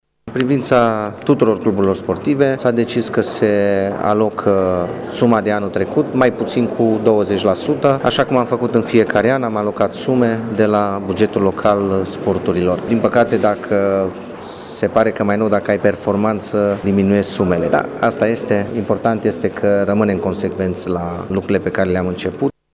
Viceprimarul Claudiu Maior spune că sumele pe care Primăria le va acorda cluburilor sportive s-au diminuat cu 20% pentru sezonul competițional 2015-2016: